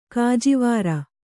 ♪ kājivāra